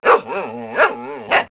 dogbrk0c.wav